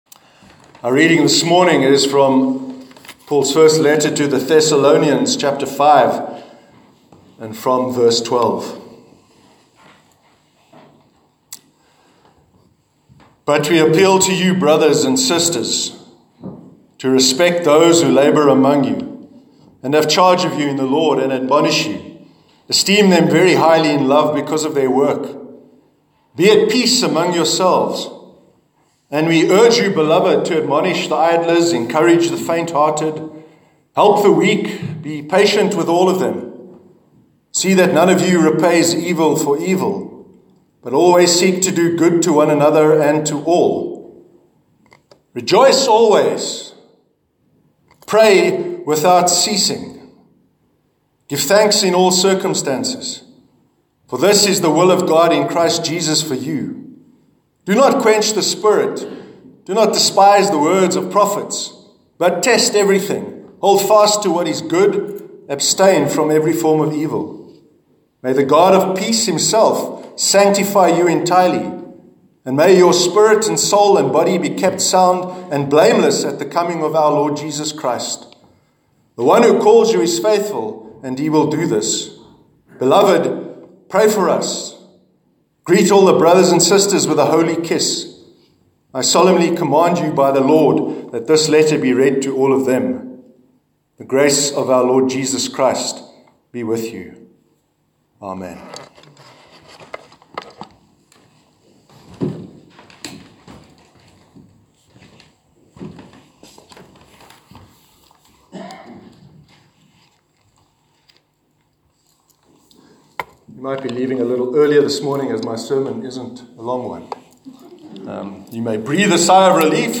Sermon on Prayer- 11th March 2018